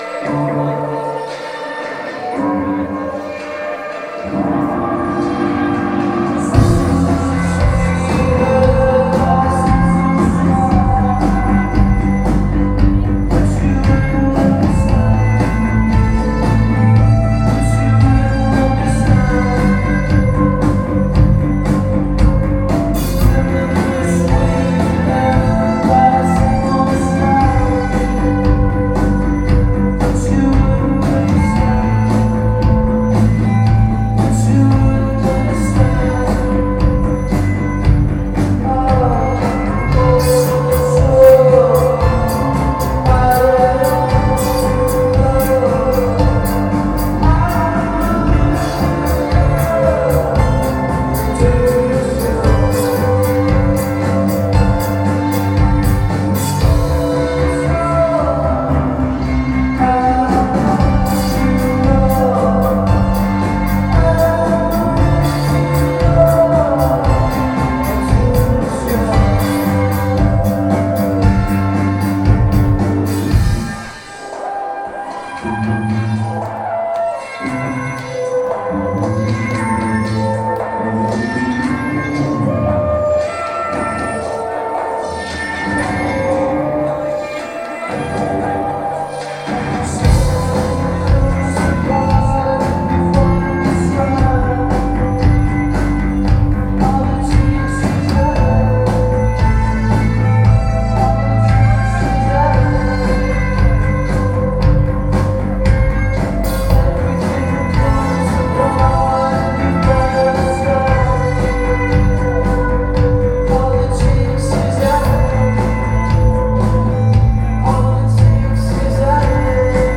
melancholy